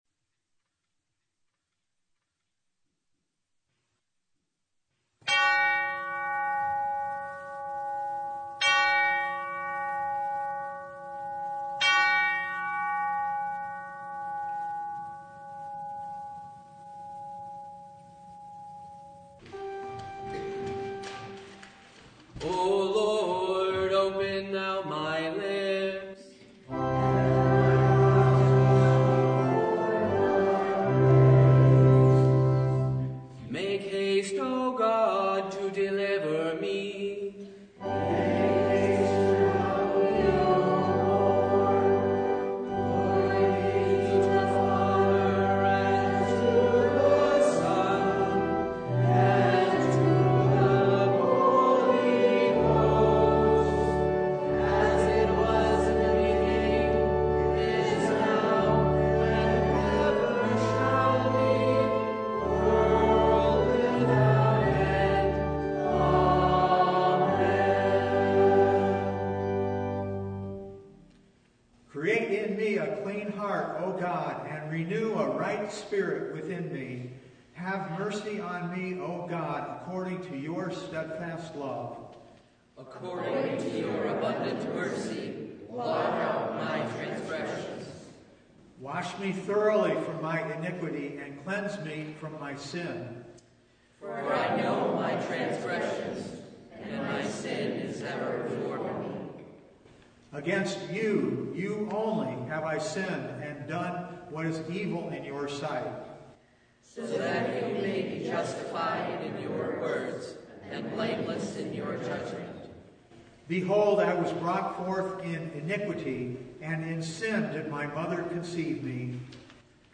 Service Type: Advent Vespers
Download Files Bulletin Topics: Full Service « Look, Listen, Believe The Third Sunday in Advent, Gaudete!